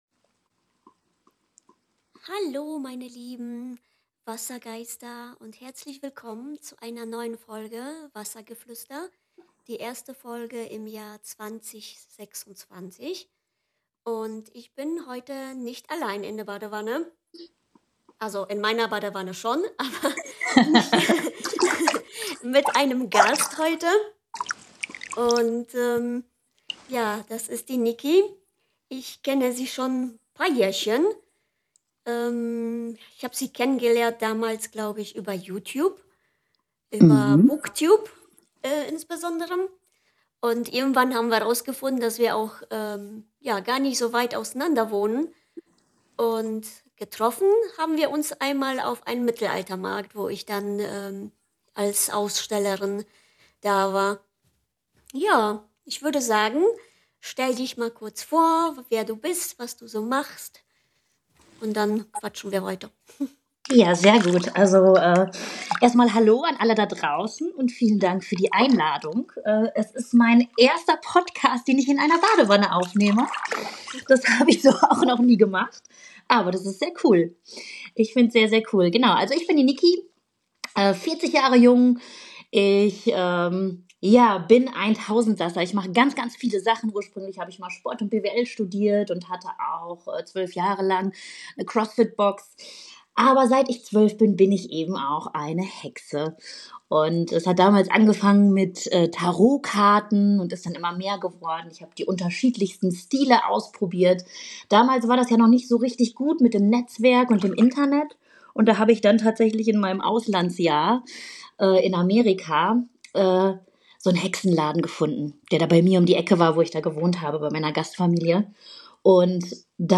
Hi Schgwester, heute bin ich nicht allein in meiner Badewanne, heute darf ich mit einer Hexenschwester über Witchcraft, Magie im Alltag und Magie von der modernen und wissenschaftlichen Seite, sporechen.
Tauch ein in unser Gespräch, viel Freude dabei.